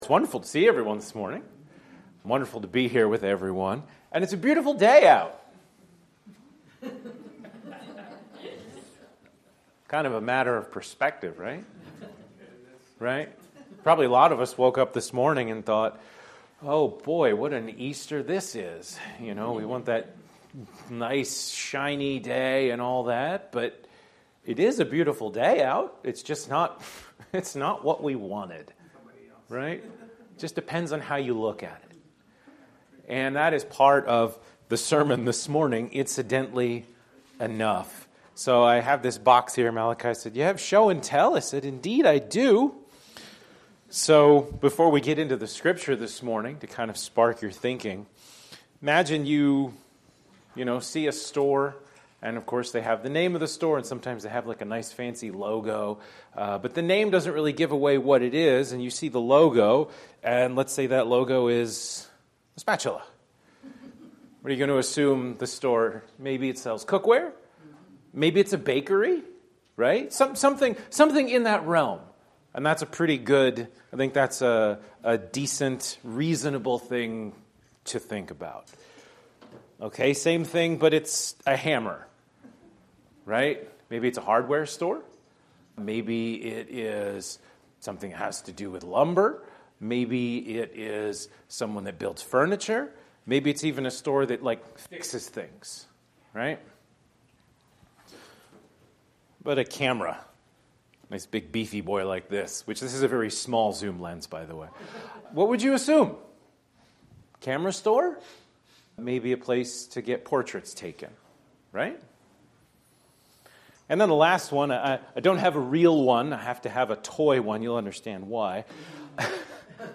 FEATURED SERMON: God Changed The Cross